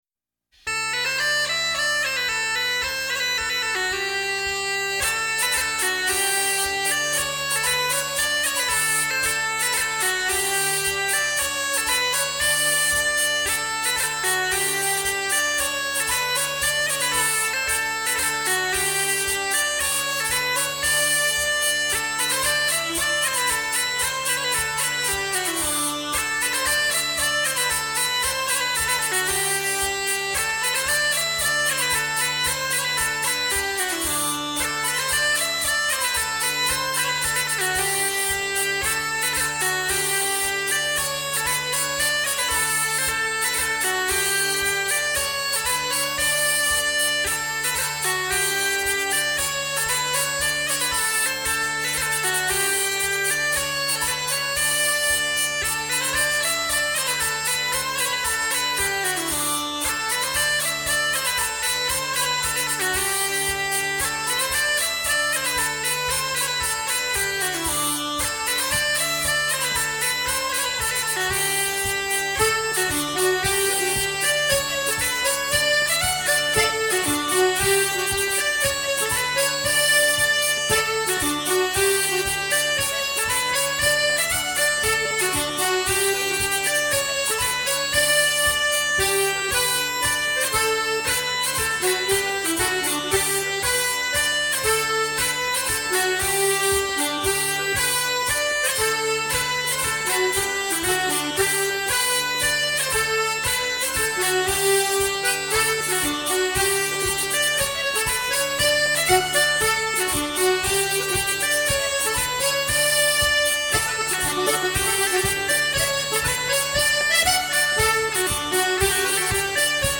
Bourrée 2 temps “La Brande” (Atelier de Danse Populaire)
Tourné au Moulin d’ Angibault en Berry